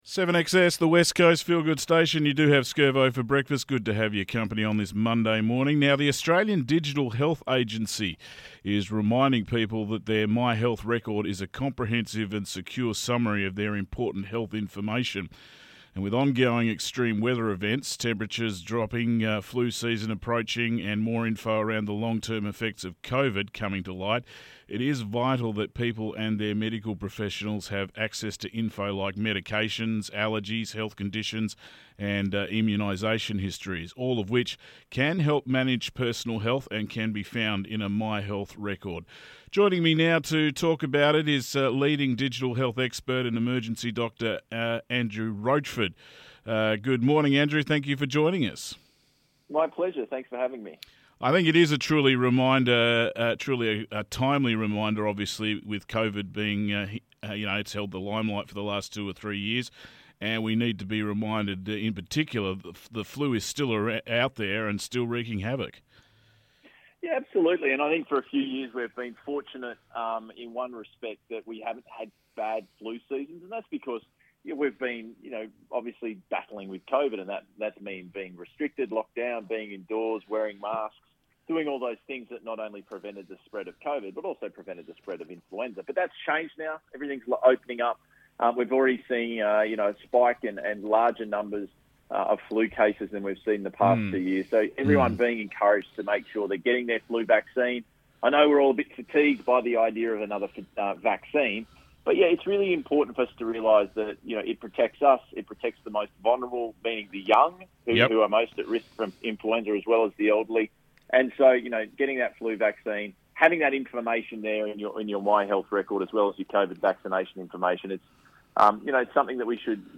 INTERVIEW WITH DOCTOR ANDREW ROCHFORD